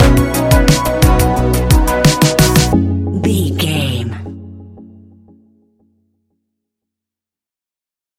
Ionian/Major
F♯
ambient
electronic
new age
downtempo
pads